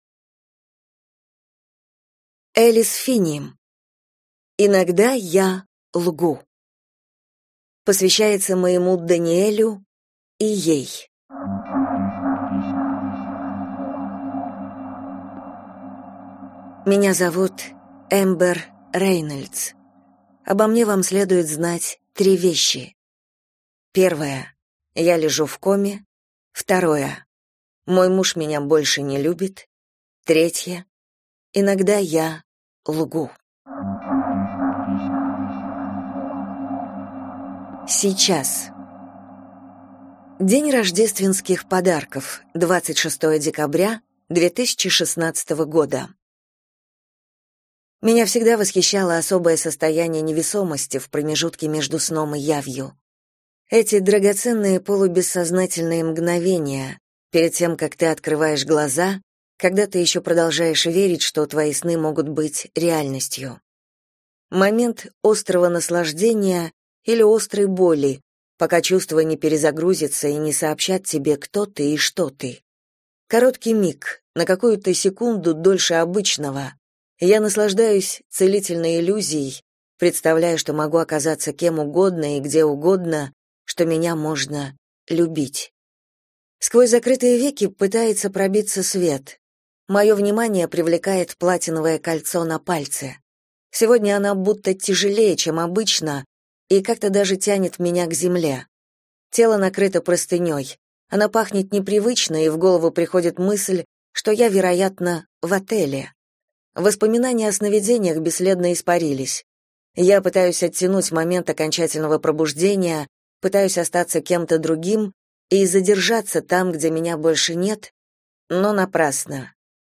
Аудиокнига Иногда я лгу | Библиотека аудиокниг